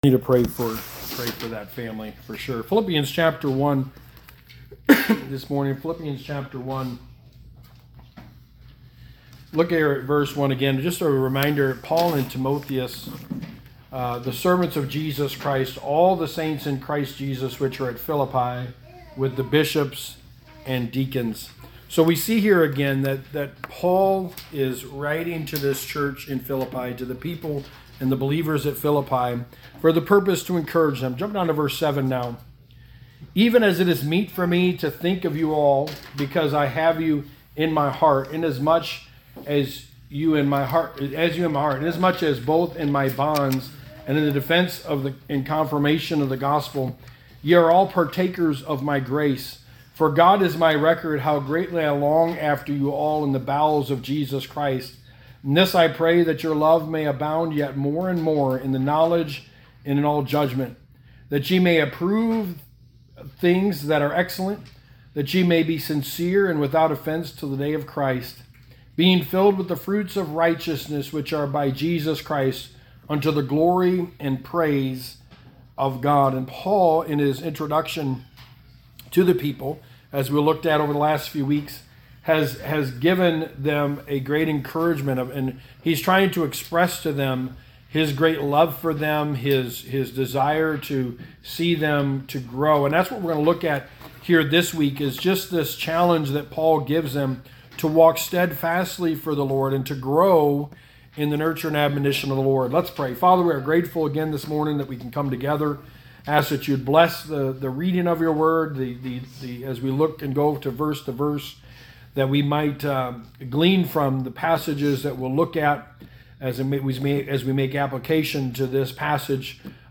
Sermon 5: The Book of Philippians: A Life That is Growing